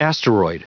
Prononciation du mot asteroid en anglais (fichier audio)
Prononciation du mot : asteroid